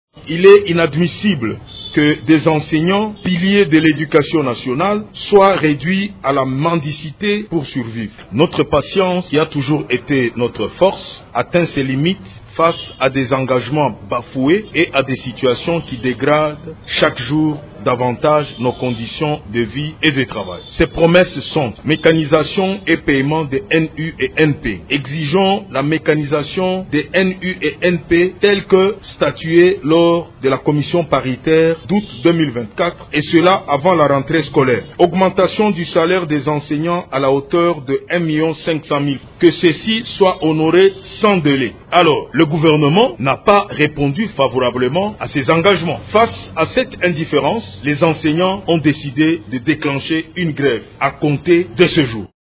La déclaration des enseignants sur le boycott de la rentrée scolaire